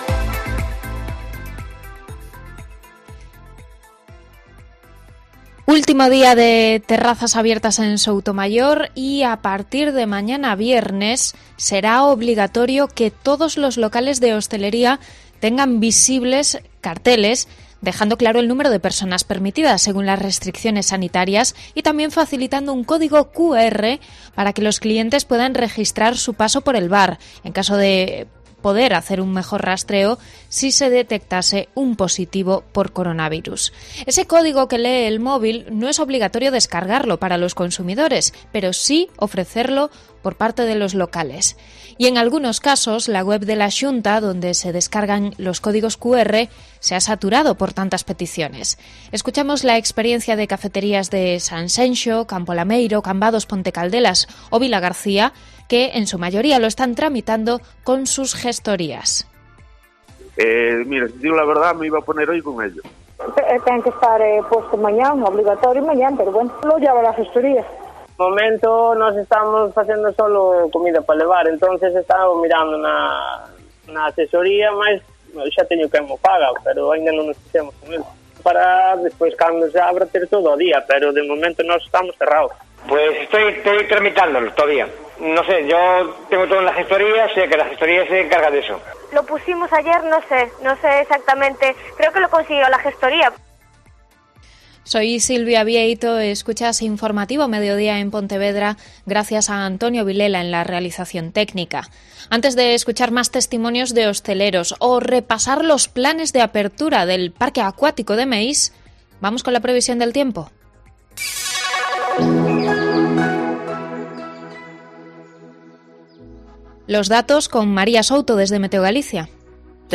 Mediodía COPE Ponteverdra (Informativo 14:20h)